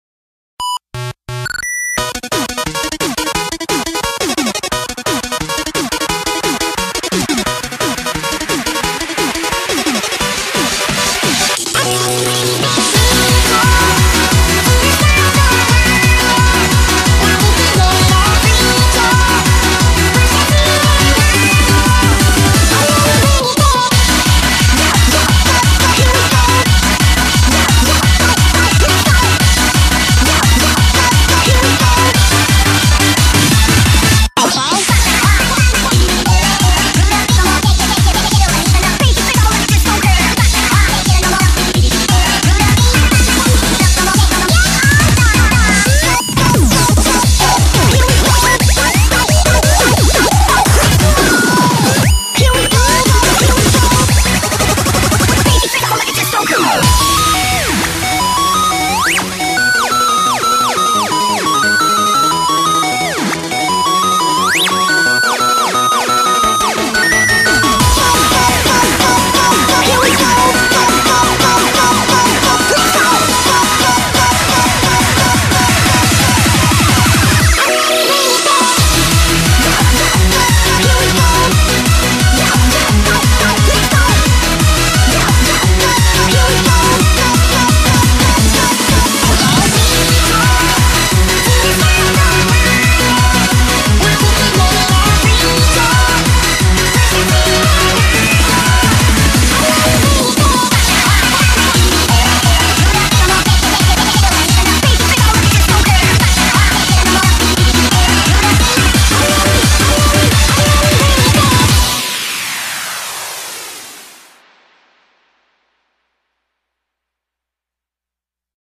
BPM175
Audio QualityPerfect (Low Quality)